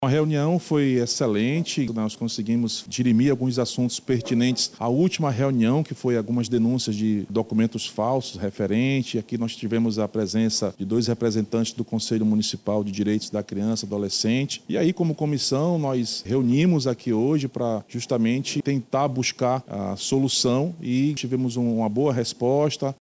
Durante o encontro, foram apresentados documentos, pelo Conselho Tutelar, que serão analisados pelos parlamentares, destaca o presidente da Comissão, vereador Raiff Matos, do PL.